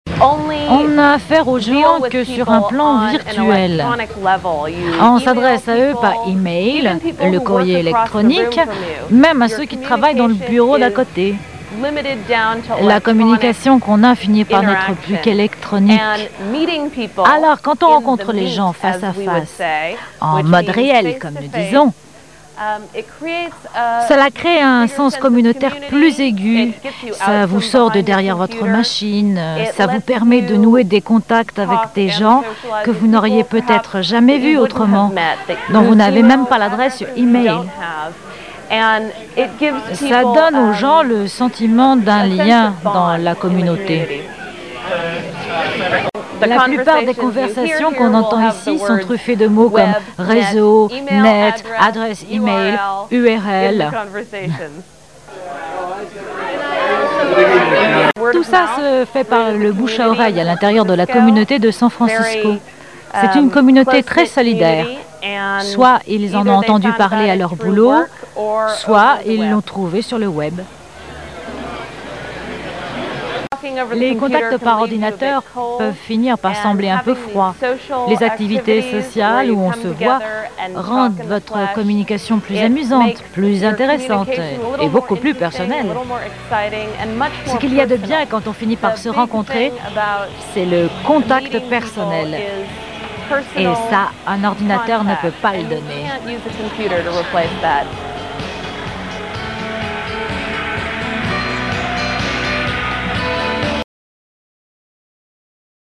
voice over pour Arte
Voix off